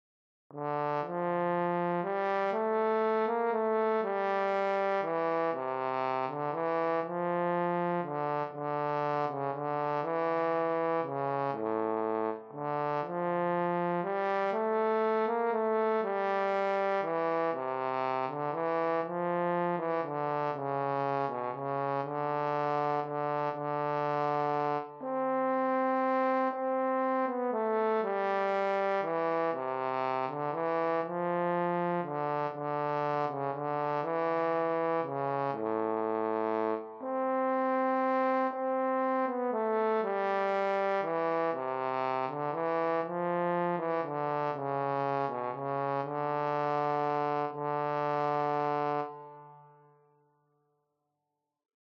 für Horn in F solo, Noten und Text als pdf, Audio als mp3